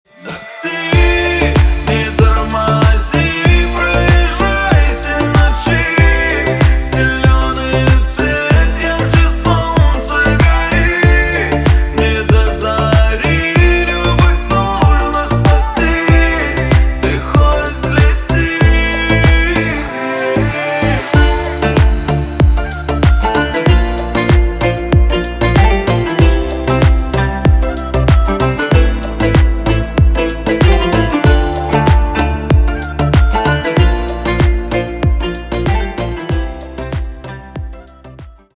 Поп Музыка # кавказские